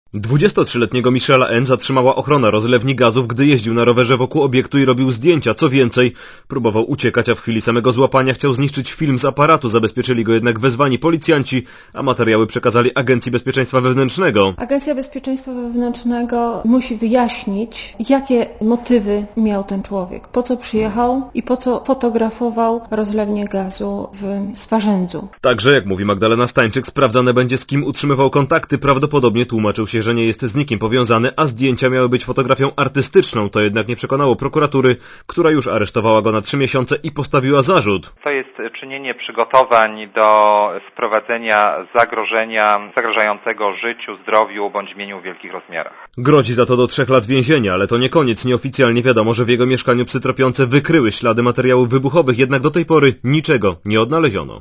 Relacja reportera Radia ZET Jak poinformowała zastępca Prokuratora Okręgowego Poznaniu Ewa Socha, sprawą zajmuje się prokuratura, a także Agencja Bezpieczeństwa Wewnętrznego.